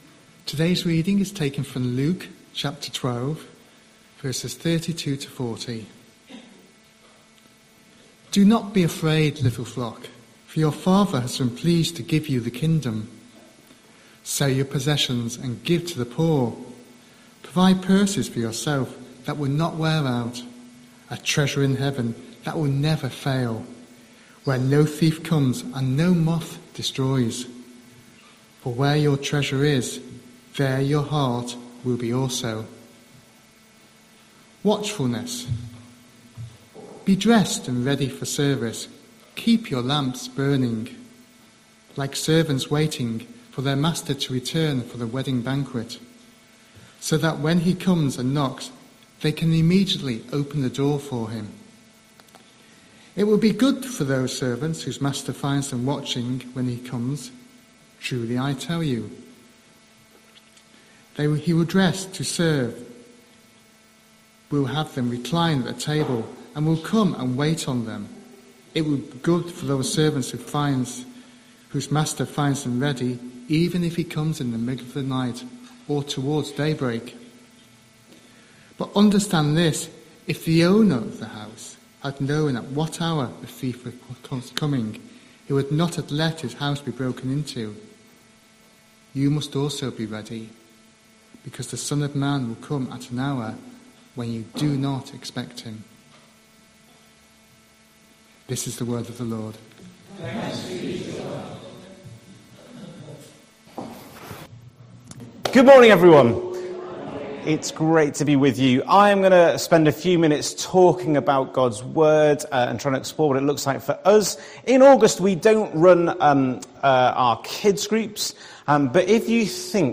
10th August 2025 Sunday Reading and Talk - St Luke's